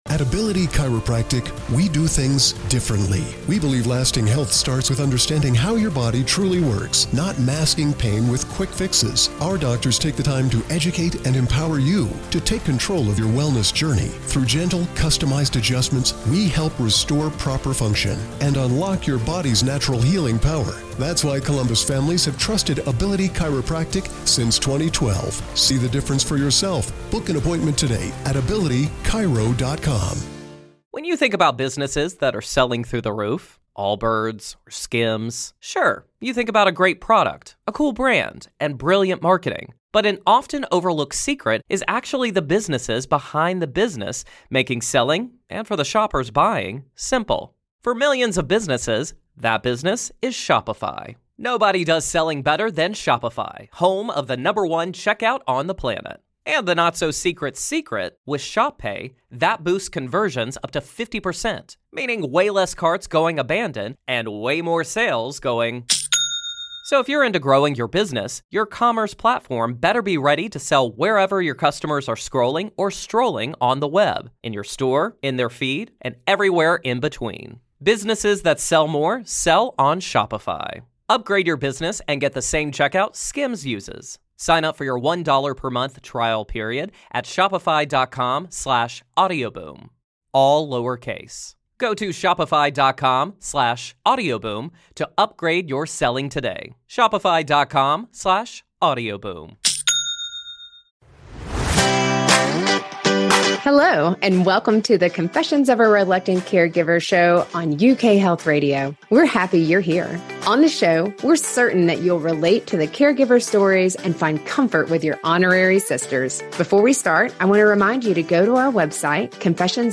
We are a trio of sisters supporting our mom who is living with Parkinson's disease, and a husband who survived cancer. We share the good, the bad, and the completely unbelievable of our caregiving journey.